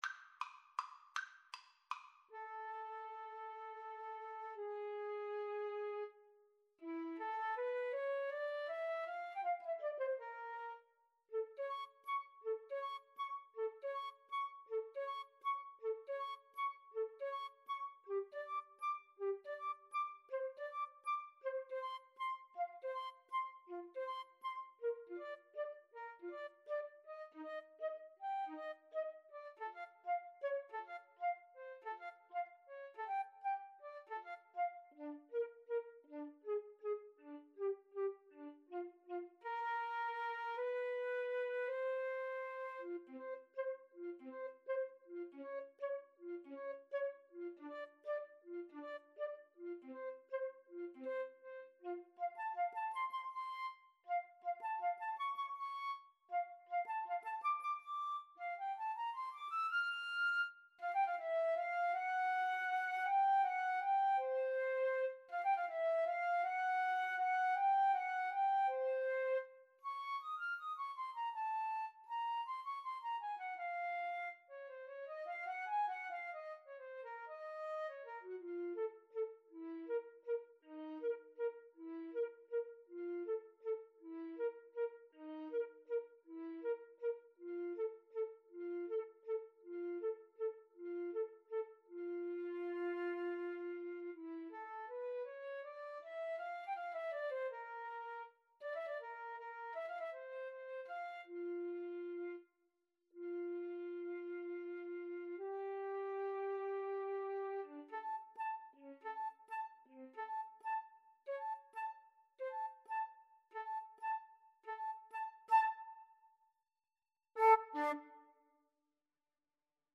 Allegretto = 160
3/4 (View more 3/4 Music)
Classical (View more Classical Flute Duet Music)